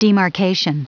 Prononciation du mot demarcation en anglais (fichier audio)
Prononciation du mot : demarcation